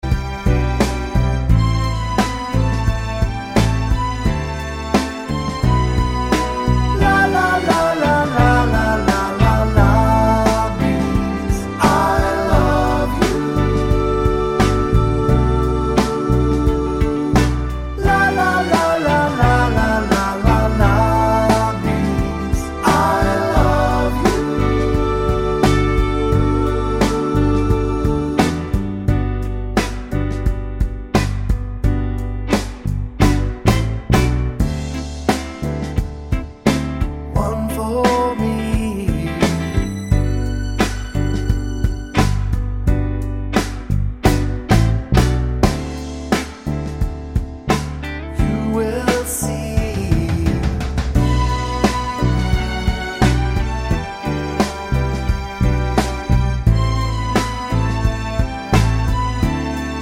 no Backing Vocals Soul / Motown 3:24 Buy £1.50